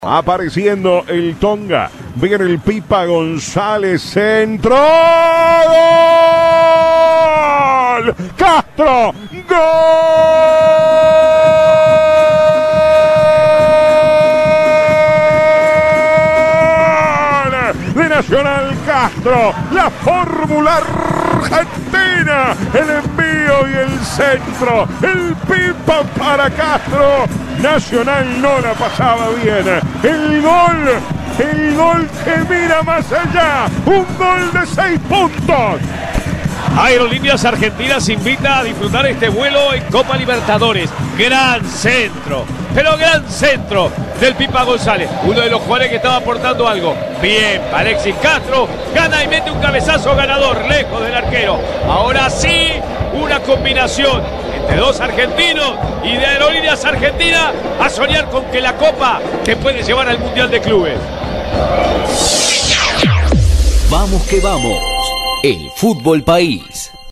Así narró el equipo de Vamos que Vamos la victoria tricolor